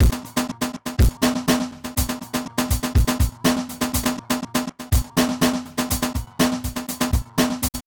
DrumLoop05.wav